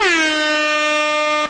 Air horn
air-horn.mp3